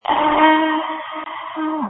Screams from December 26, 2020
• When you call, we record you making sounds. Hopefully screaming.